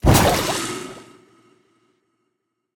Minecraft Version Minecraft Version snapshot Latest Release | Latest Snapshot snapshot / assets / minecraft / sounds / mob / husk / convert2.ogg Compare With Compare With Latest Release | Latest Snapshot